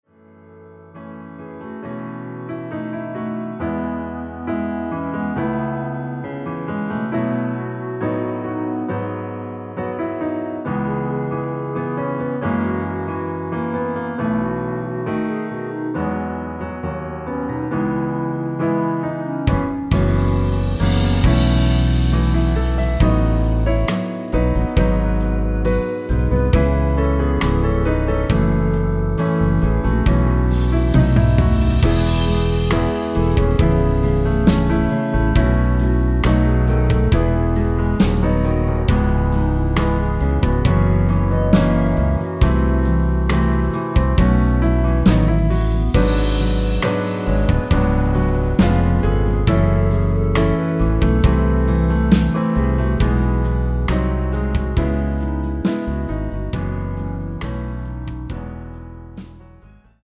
(-3)멜로디 포함된 MR 입니다.(미리듣기 참조)
앞부분30초, 뒷부분30초씩 편집해서 올려 드리고 있습니다.
곡명 옆 (-1)은 반음 내림, (+1)은 반음 올림 입니다.
(멜로디 MR)은 가이드 멜로디가 포함된 MR 입니다.